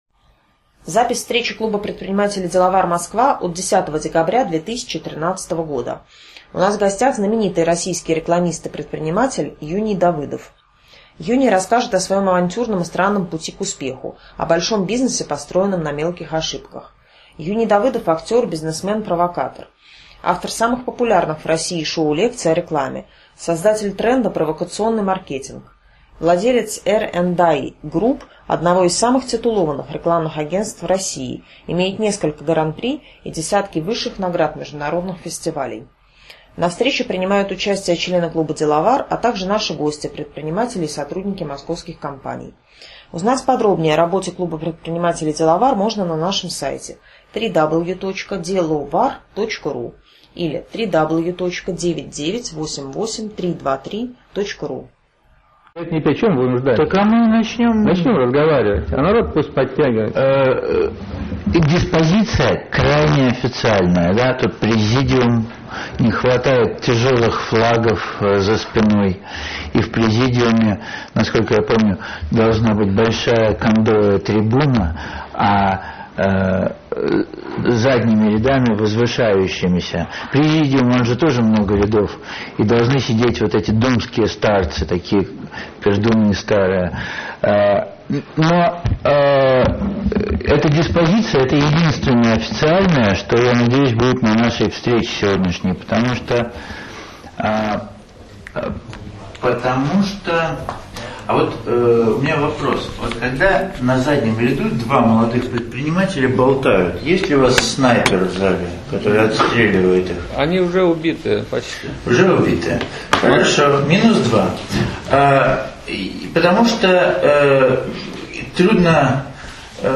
10 декабря 2013 года мы провели последнюю в 2013 году открытую встречу клуба предпринимателей "Деловар" в Москве.